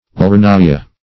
Lernaea \Ler*n[ae]"a\ (l[~e]r*n[=e]"[.a]), n. [NL., fr. L.